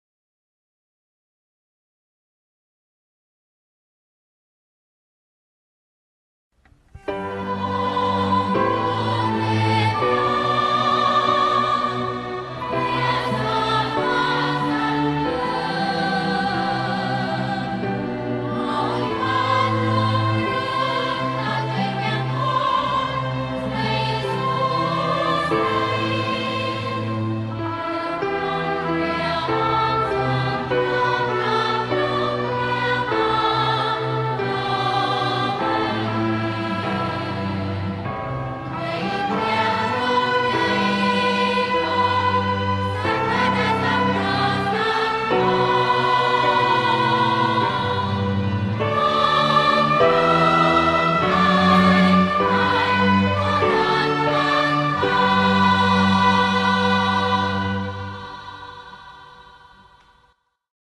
Национальный гимн Камбоджи